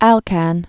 (ălkăn)